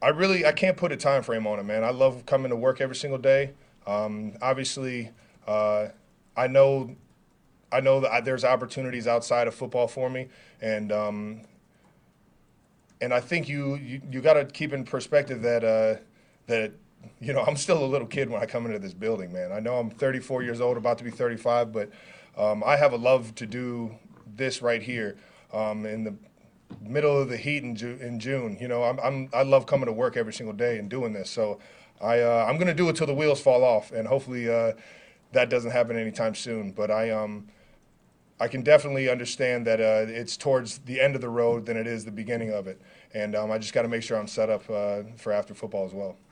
The Kansas City Chiefs playmaker told reporters at minicamp practice on Tuesday that he will keep playing “until the wheels fall off.”